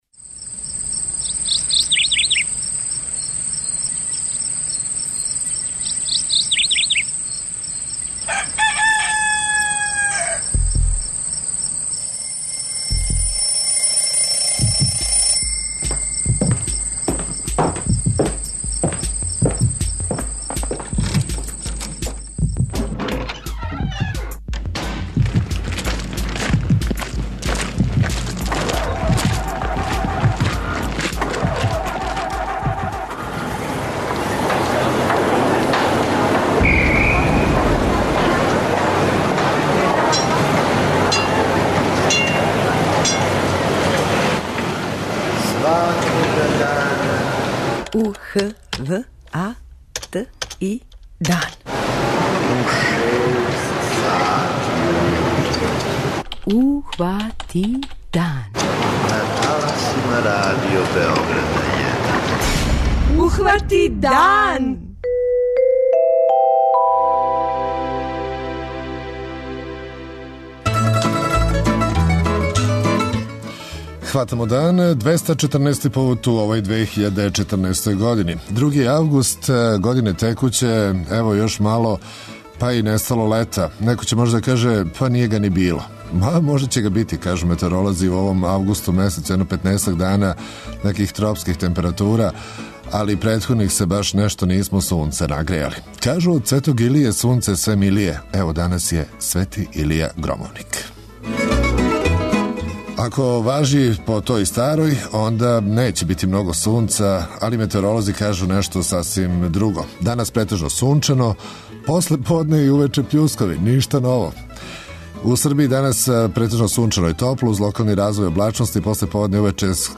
Осим актуелних сервисних информација, чућете председника општине Топола, Драгана Јовановића, који ће нам пренети тренутну ситуацију у граду после невремена које је задесило тај крај.